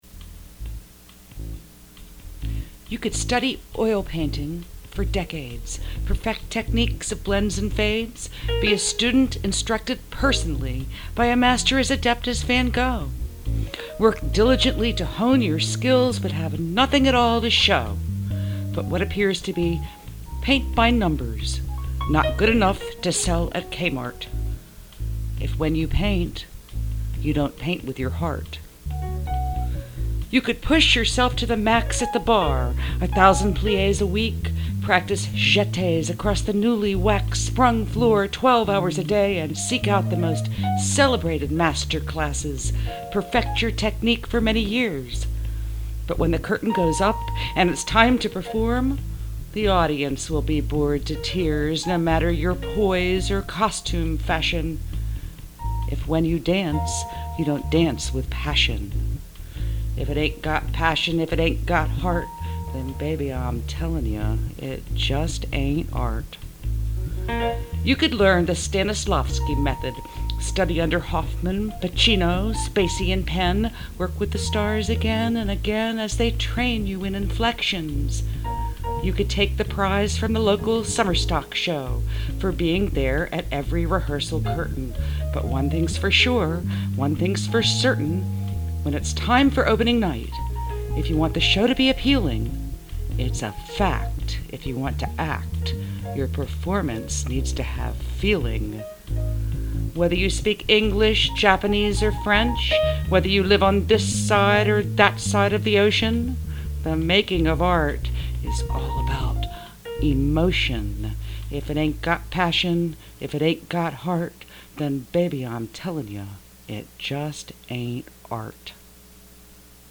bass and piano
me on vocals